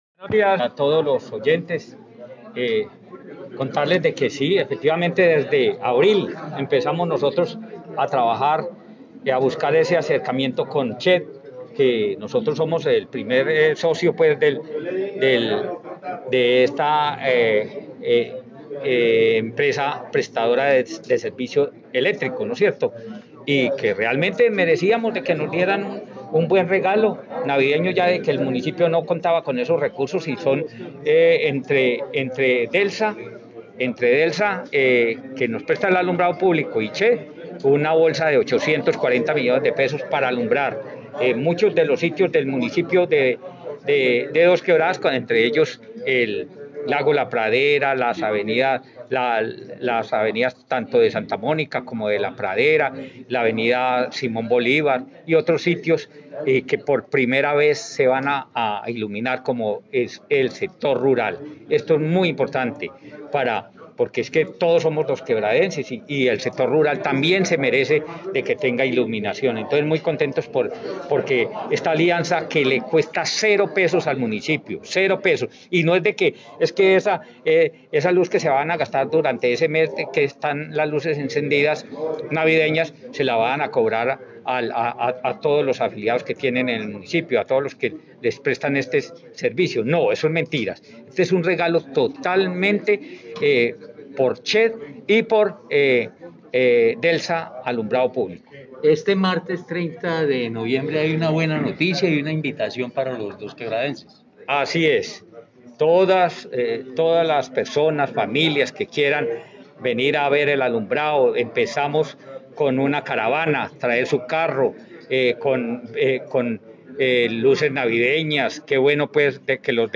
Escuchar Audio: Diego Ramos Castaño, Alcalde de Dosquebradas.
Diego_Ramos_Castan_o_alcalde_de_Dosquebradas.mp3